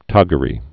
(tŏgə-rē, tôgə-)